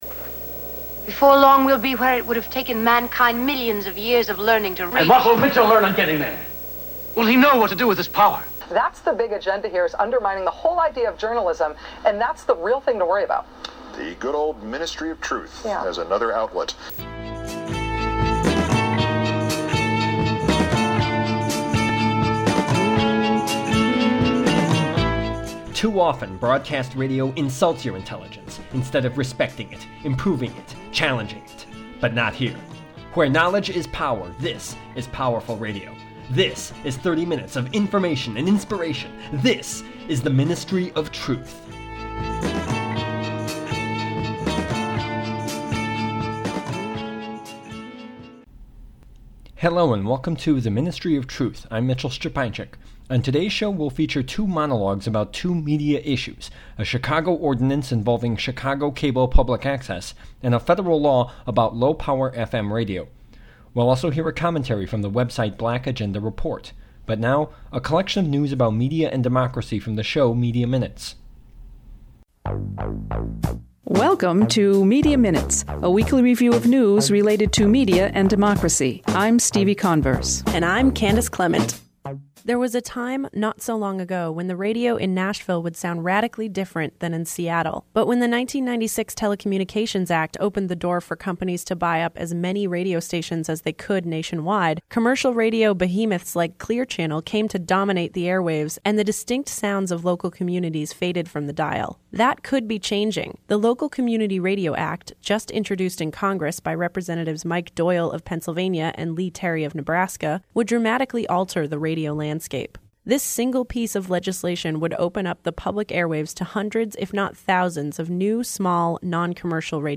The Ministry of Truth: Monologue about CAN TV and the Local Community Radio Act